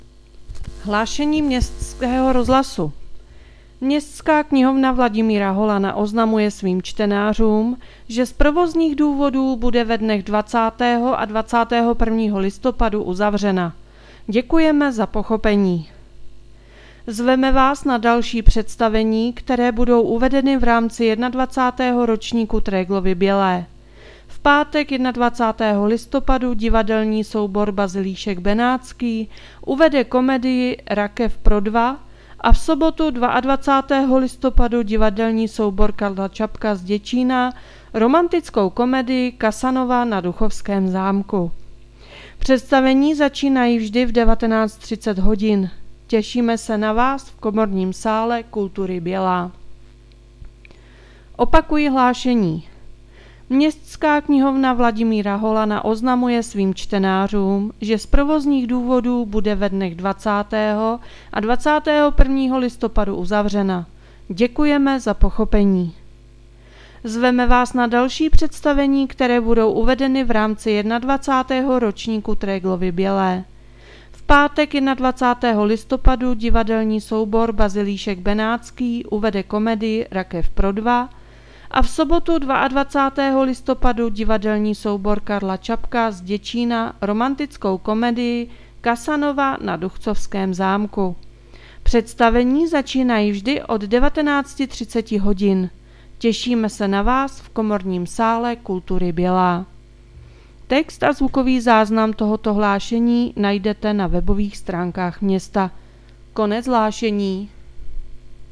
Hlášení městského rozhlasu 19.11.2025